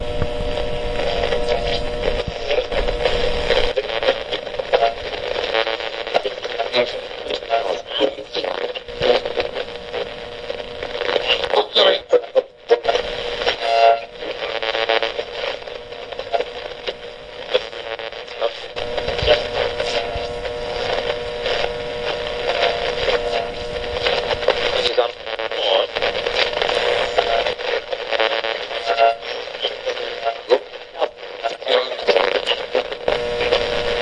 无线电干扰
描述：短无线电干扰
标签： 收音机 静态 频率 干扰 上午 噪音
声道立体声